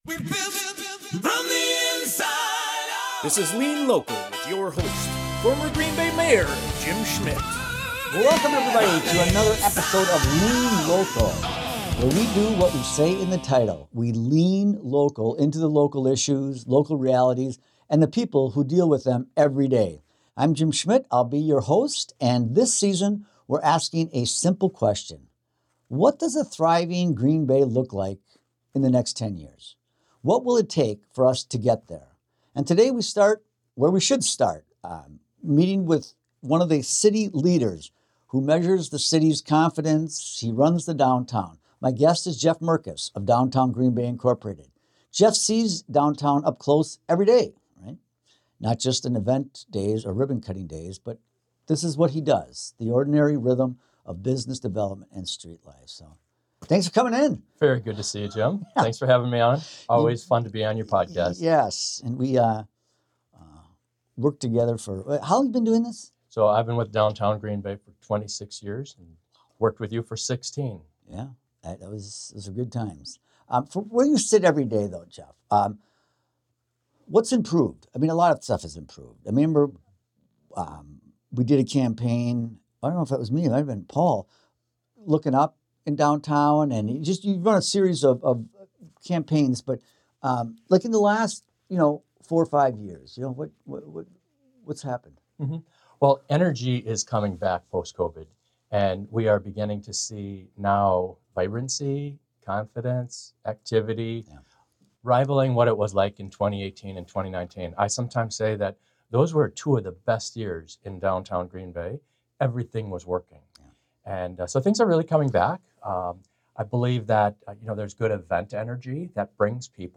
Dive into the heart of community issues with 'Lean Local,' hosted by former Green Bay Mayor Jim Schmitt. This refreshing political and issues program bypasses traditional left-versus-right rhetoric.